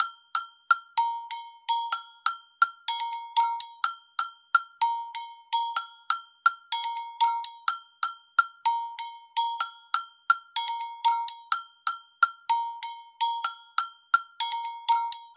Народный бразильский инструмент Агого: Agogo (125 BPM)
Тут вы можете прослушать онлайн и скачать бесплатно аудио запись из категории «Перкуссии (Percussion Loops)».